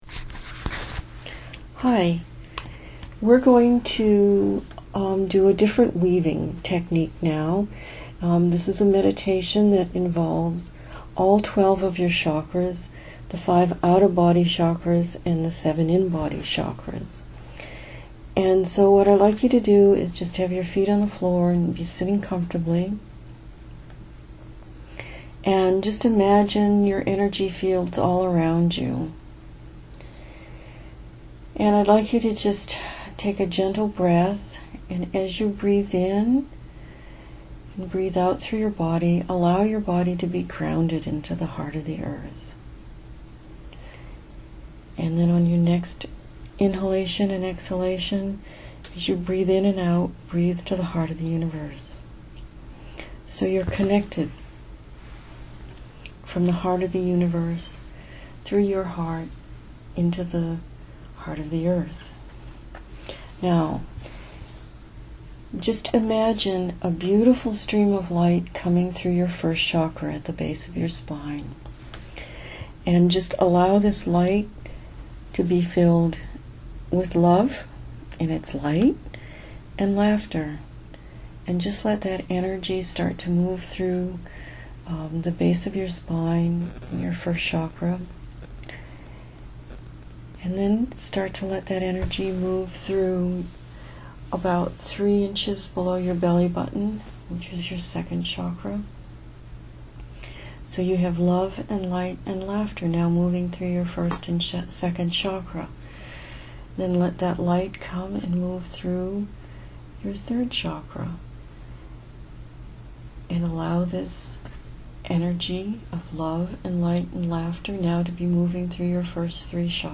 weaving-meditation-6-21-17.mp3